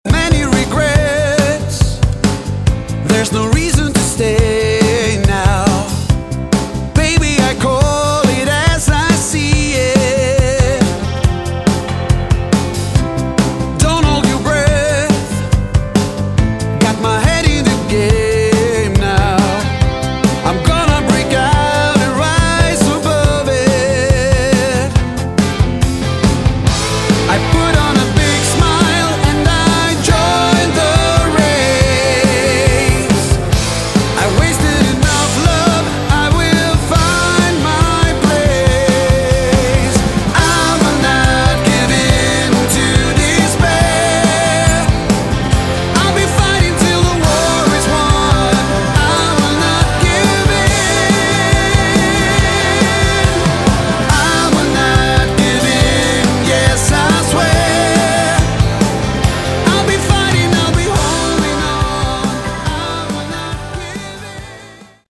Category: AOR
Vocals
Guitars
Bass
Keyboards
Drums